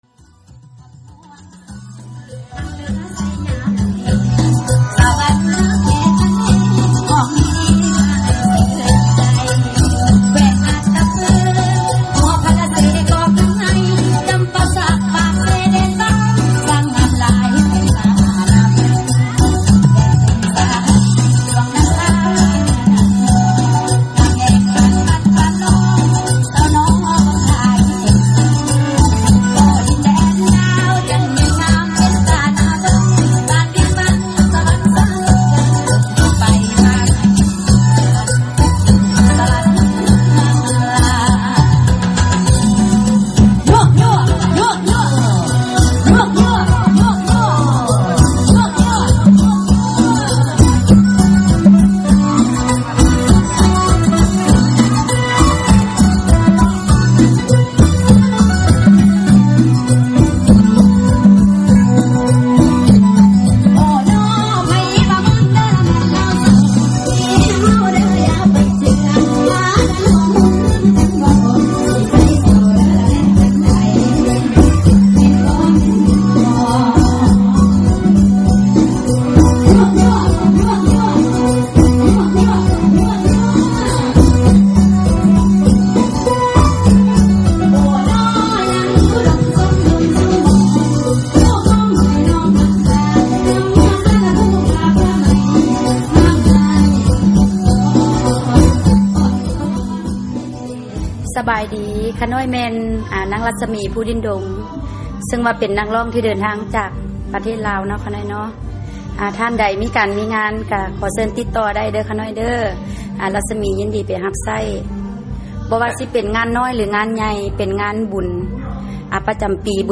ນັກຮ້ອງຮັບເຊີນຈາກ ສປປ ລາວ